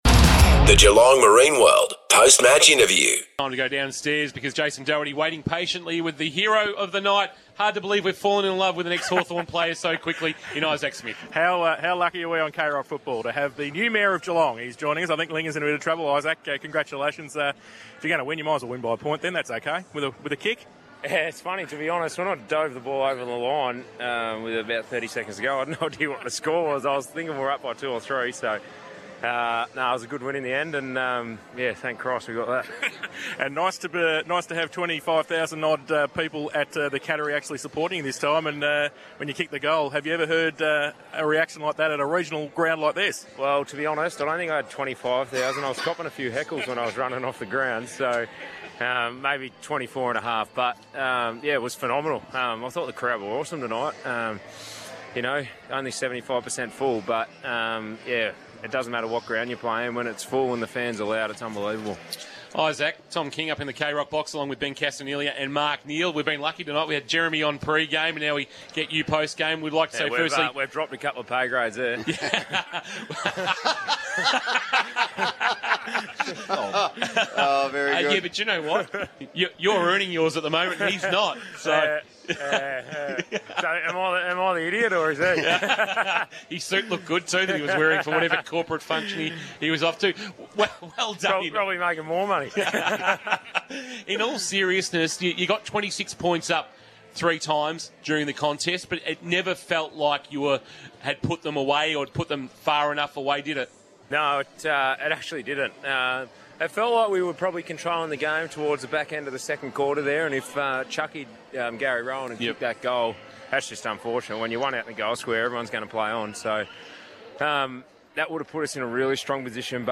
POST-MATCH INTERVIEW: ISAAC SMITH - Geelong